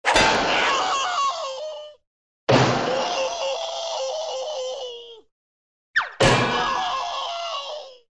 Звуки падения
Звук крика при падении (смешно)